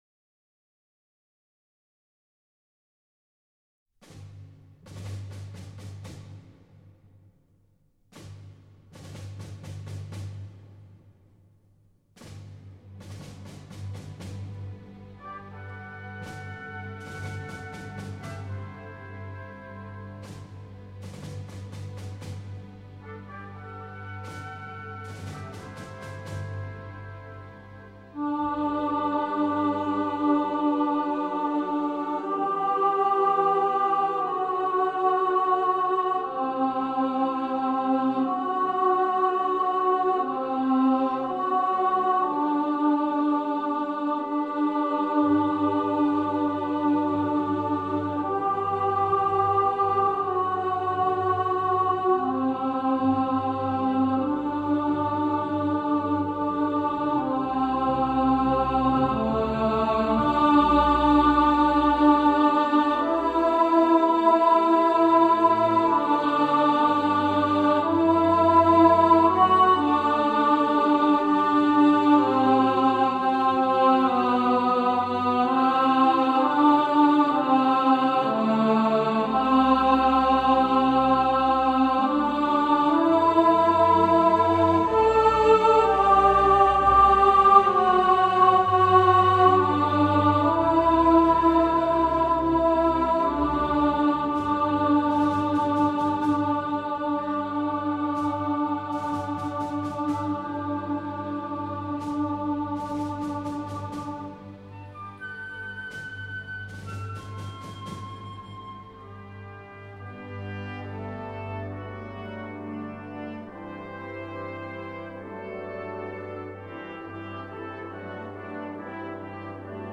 Hymn To The Fallen – Alto | Ipswich Hospital Community Choir
Hymn-To-The-Fallen-Alto.mp3